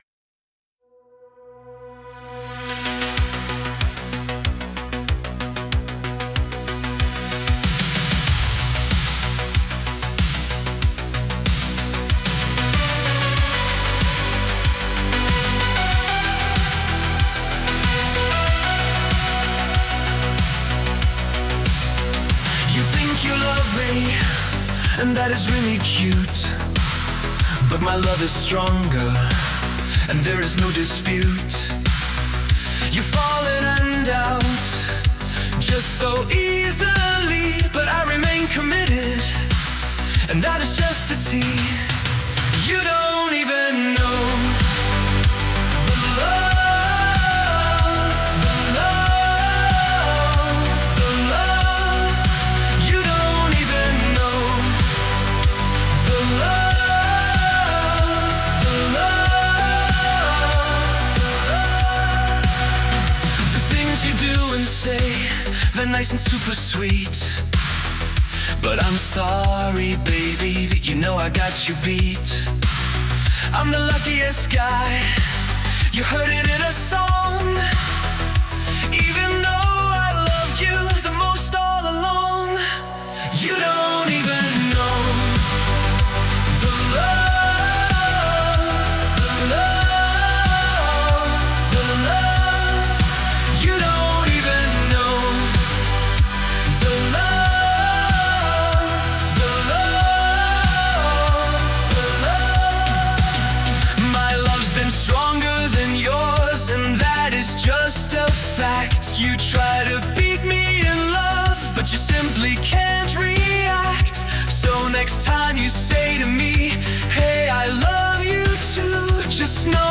I wanted more intense shouting at the end.